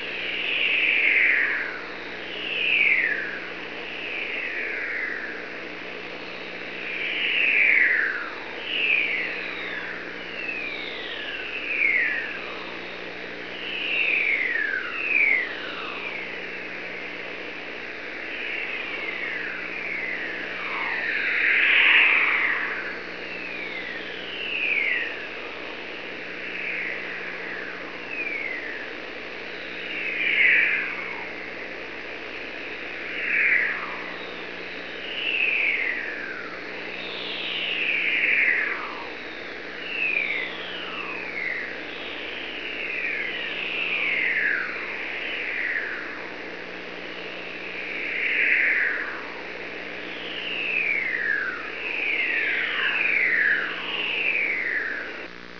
A similar recording of an S Burst is available here .